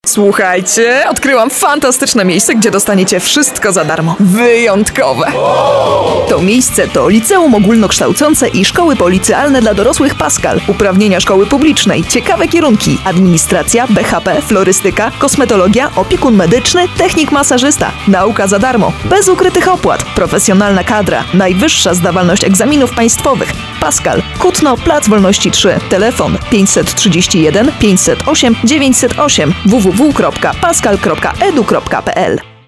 Female 20-30 lat
A distinctive and flexible voice with a wide range - from matte, deep tones to sharp, expressive sounds.
Nagranie lektorskie